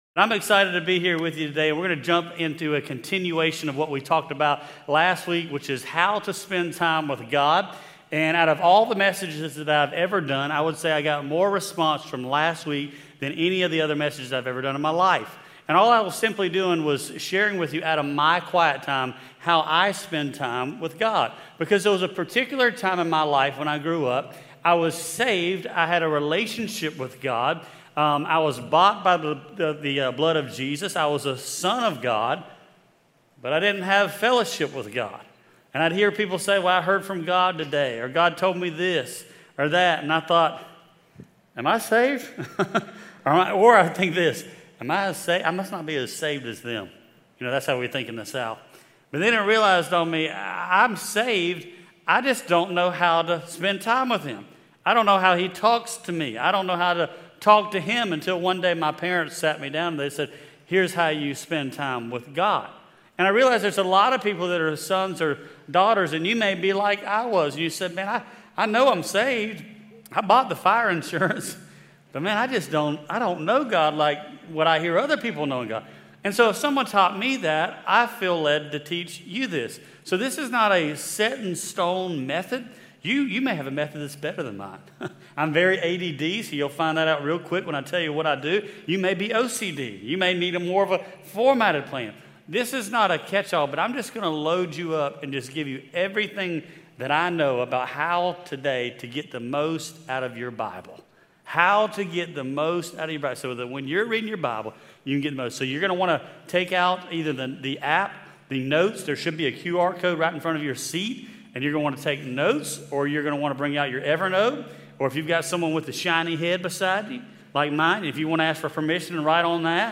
special message